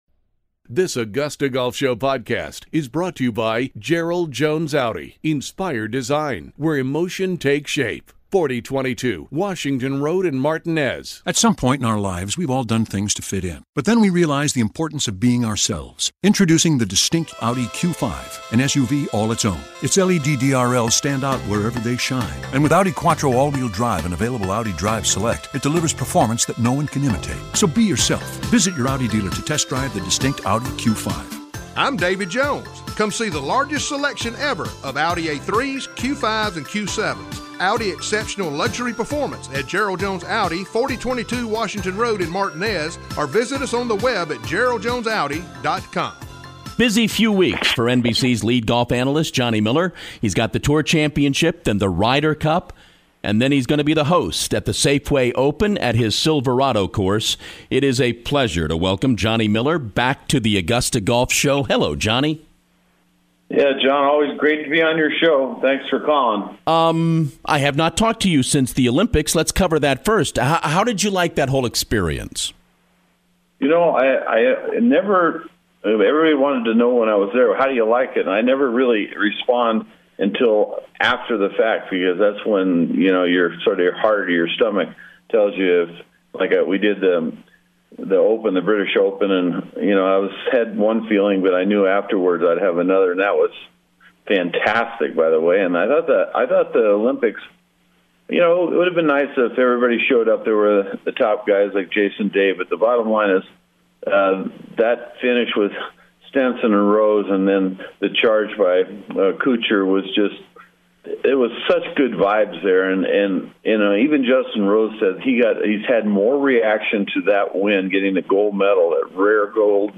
Johnny Miller: The AGS Interview